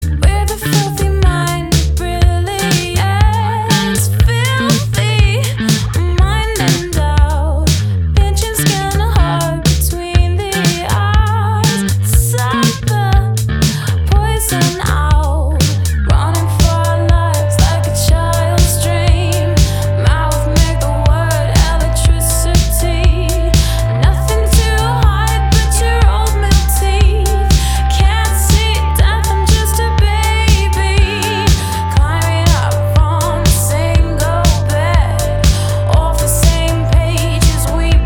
indie pop
красивый женский вокал
Indie